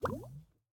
drip_lava_cauldron1.ogg